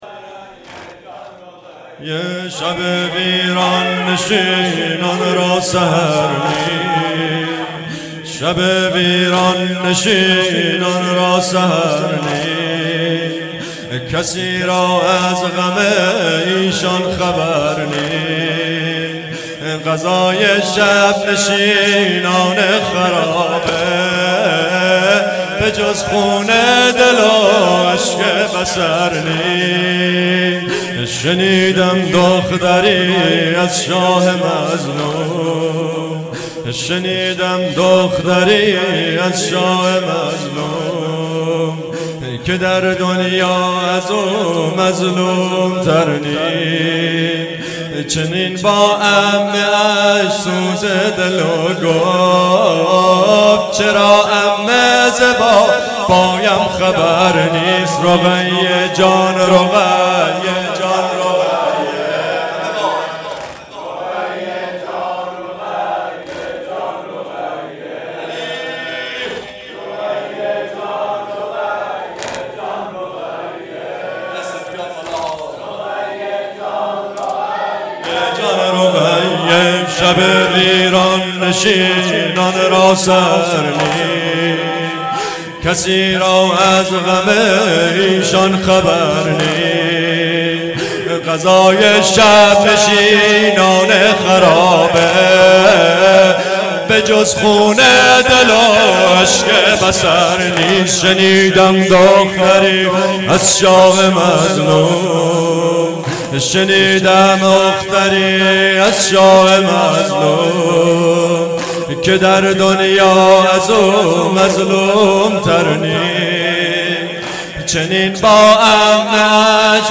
مکان:شهرک شهدا نبش پارک لاله هیئت علمدار سنقر